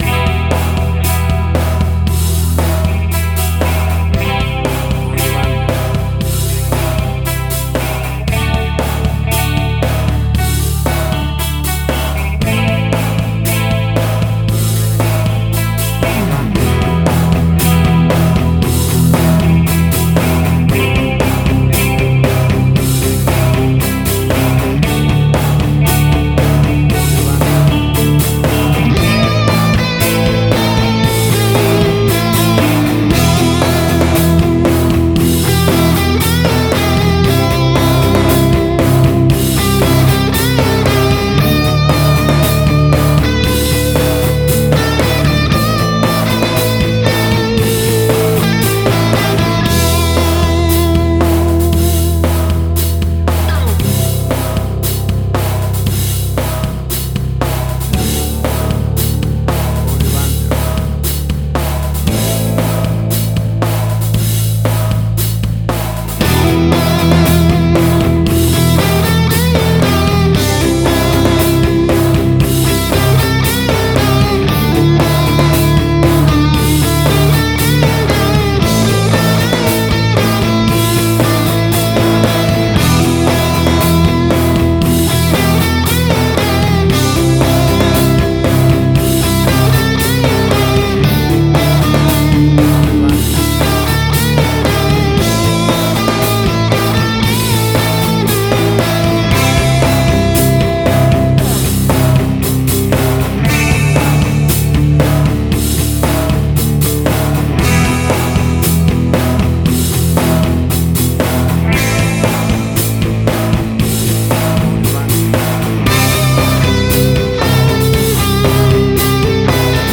Hard Rock, Similar Black Sabbath, AC-DC, Heavy Metal.
Tempo (BPM): 115